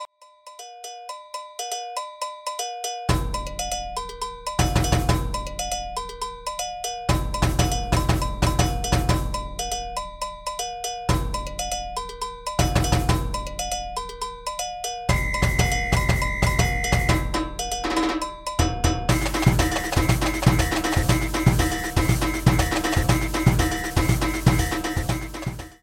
Established in 1993 as a world percussion group